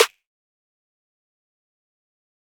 Love Snare.wav